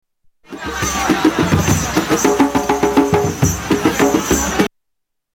Vappu drums
Tags: Travel Sounds of Finland Finland Vacation Helsinki